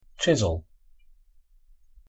Pronunciation En Chisel Pronunciation for the world "Chisel" Reference: Team, Forvo.